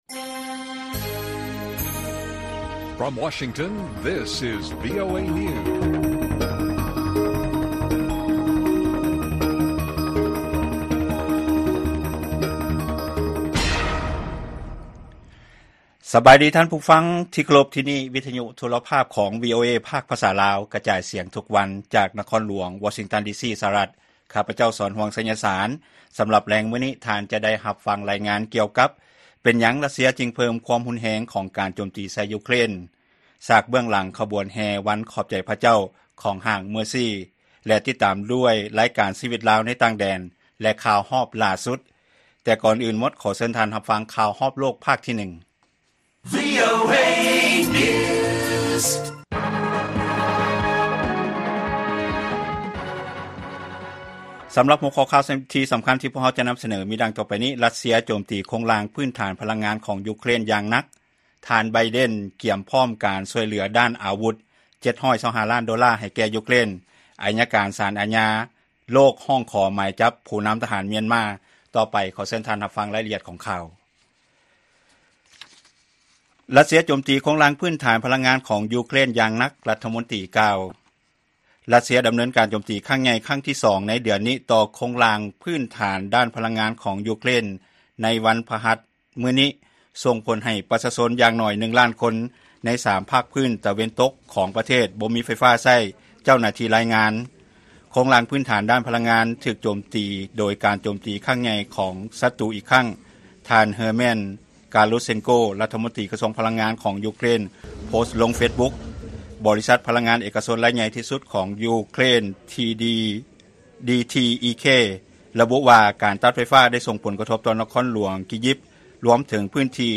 ລາຍການກະຈາຍສຽງຂອງວີໂອເອລາວ: ຣັດເຊຍ ເພີ້ມການໂຈມຕີຂະໜາດໃຫຍ່ຕໍ່ພື້ນຖານໂຄງລ່າງດ້ານພະລັງງານຂອງ ຢູເຄຣນ